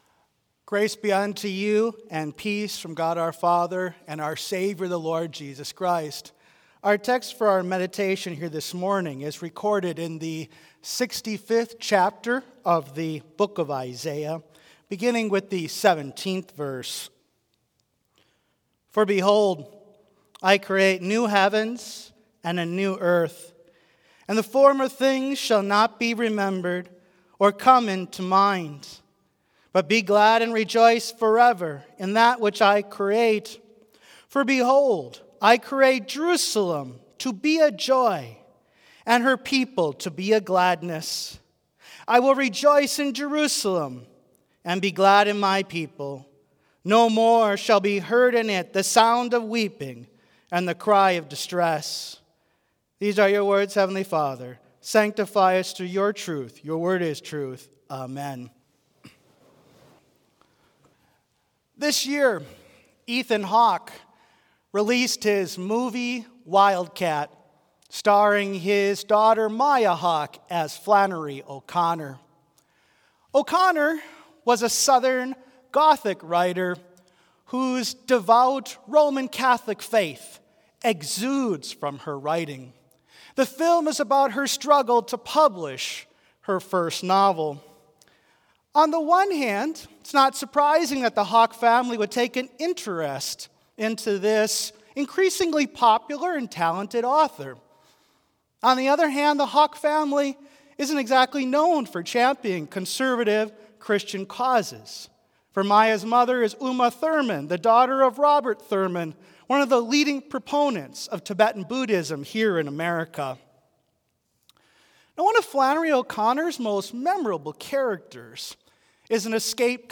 Complete service audio for Chapel - Monday, November 25, 2024
Prelude Hymn 359 - Worthy is Christ, the Lamb Who Was Slain